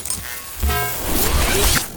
bsword1.ogg